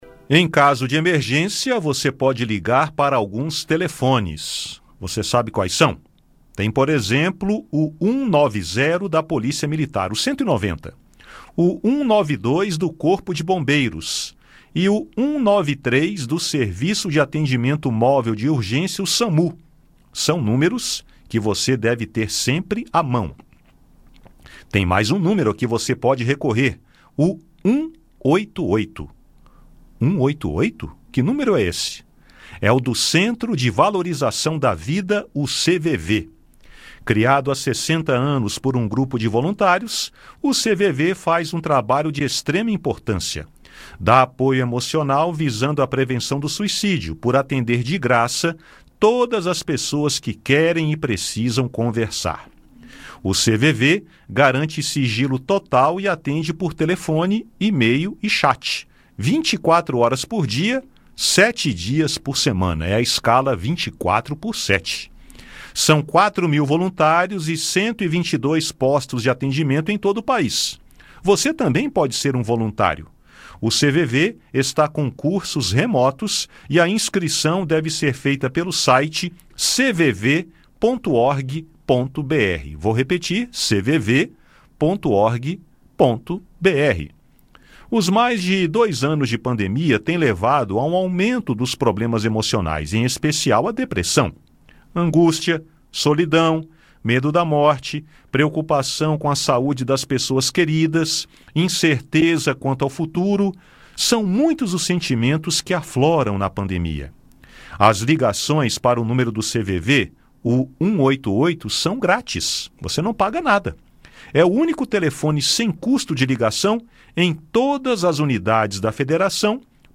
Ouça no bate-papo